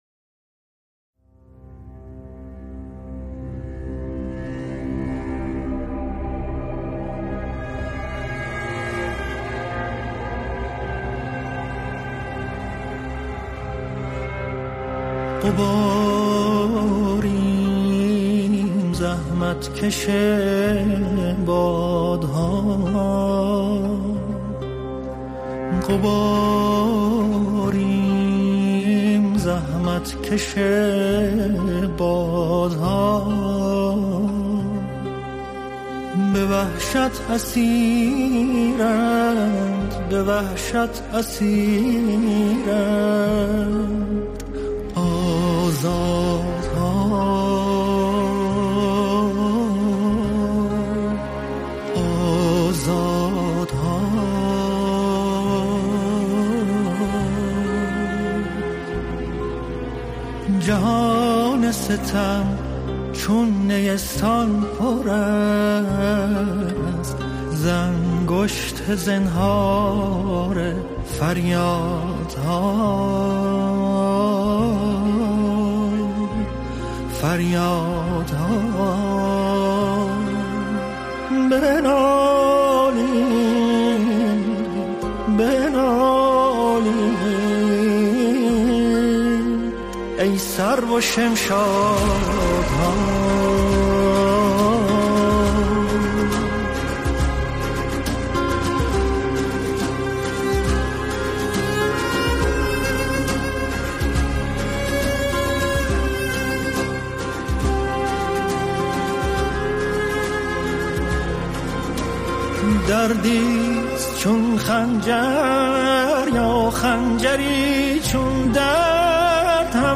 حال‌و‌هوایی اجتماعی و اعتراضی